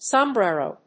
音節som・bre・ro 発音記号・読み方
/səmbré(ə)roʊ(米国英語), sɑmbré(ə)roʊ(英国英語)/